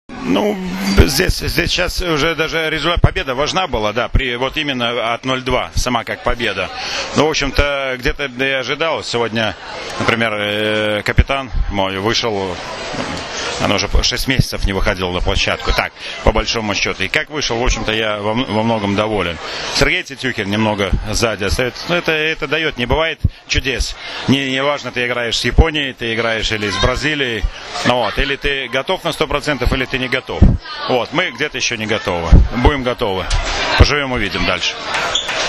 IZJAVA VLADIMIRA ALEKNA